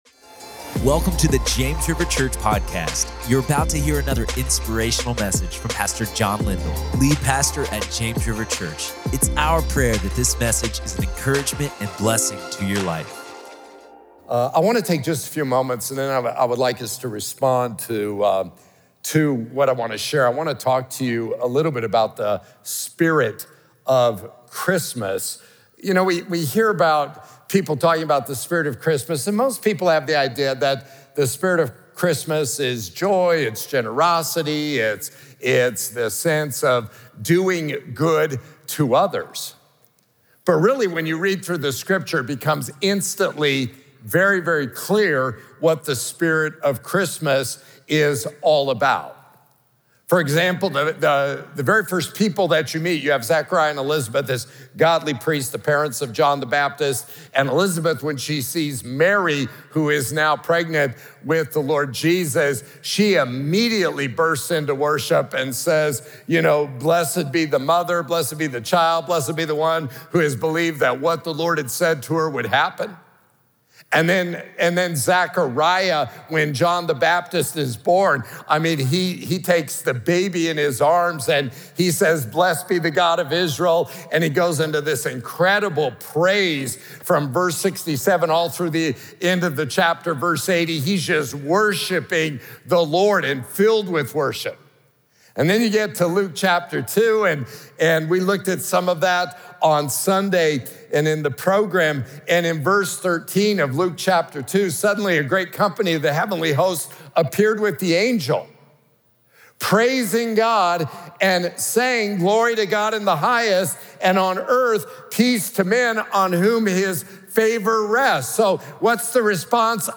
The Spirit of Christmas: Worship (Pt. 1) | Prayer Meeting